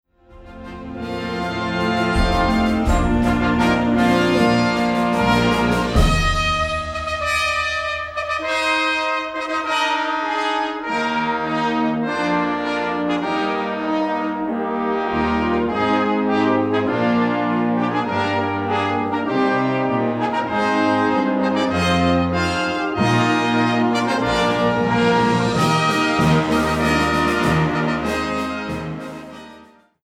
Gattung: Eröffnungsfanfare
Besetzung: Blasorchester